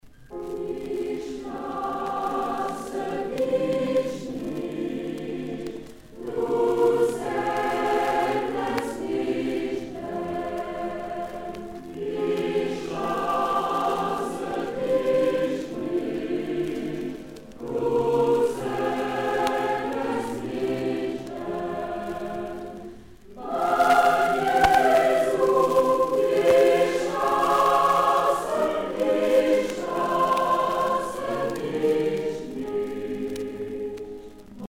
Motet
Rassemblement des chorales A Coeur Joie